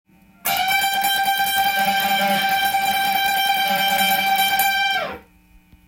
Gミクソリディアンスケールを例にフレーズを作ってみました。
ひたすら同じ場所でチョーキングしながら繰り返すだけというフレーズです。